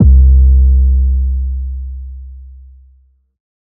808_Oneshot_Descend_C
808_Oneshot_Descend_C.wav